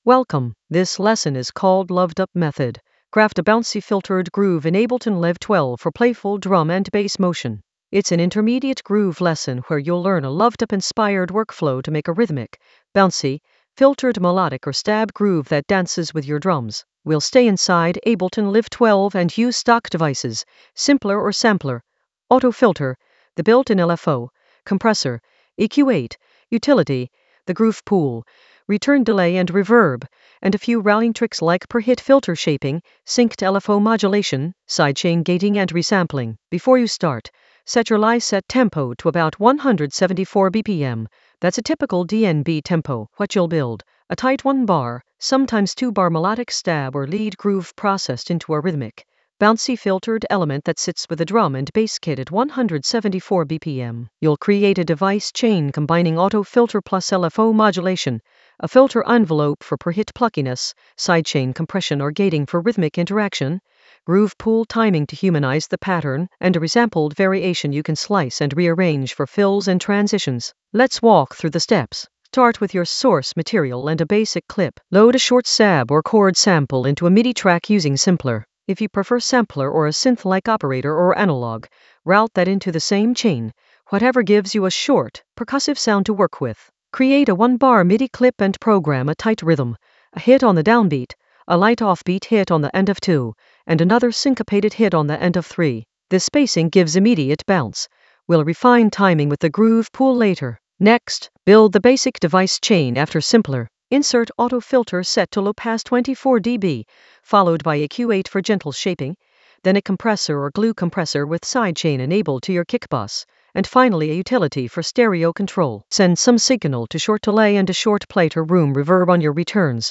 An AI-generated intermediate Ableton lesson focused on Luvdup method: craft a bouncy filtered groove in Ableton Live 12 for playful drum and bass motion in the Groove area of drum and bass production.
Narrated lesson audio
The voice track includes the tutorial plus extra teacher commentary.